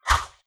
Close Combat Swing Sound 55.wav